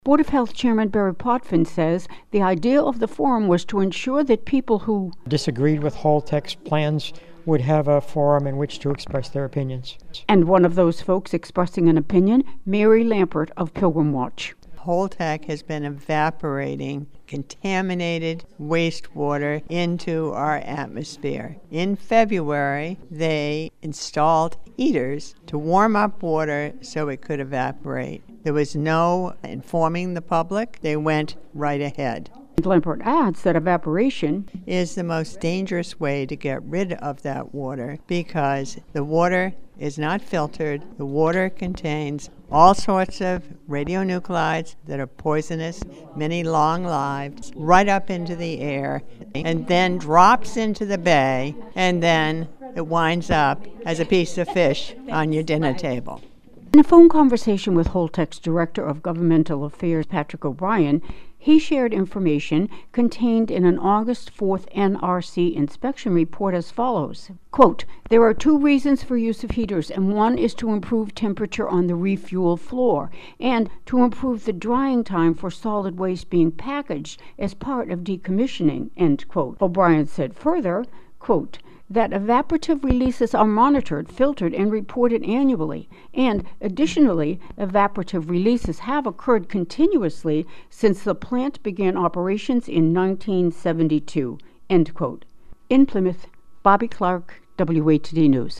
— WATD 95.9 News & Talk Radio, South Shore Massachusetts